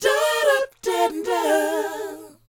DOWOP A#4B.wav